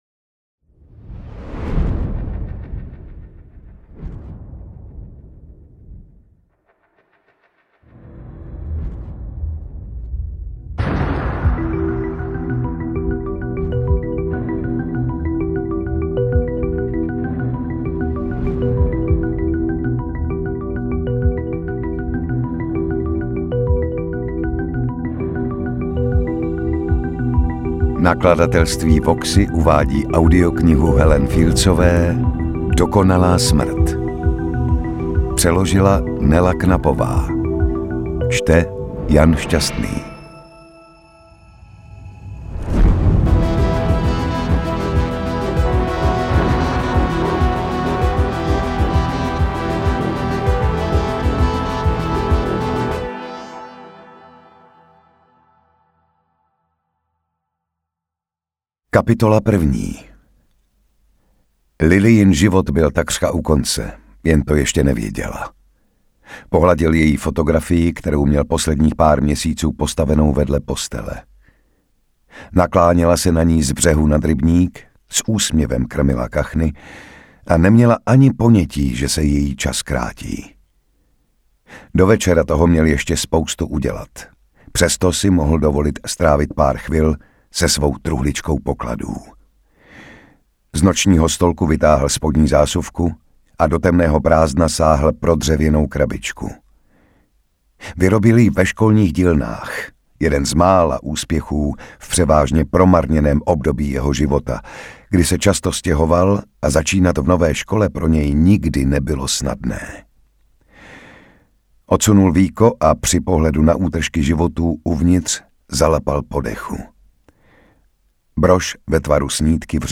Interpret:  Jan Šťastný
Děj je poslouchatelný a Jan Šťastný jako interpret dokonalý.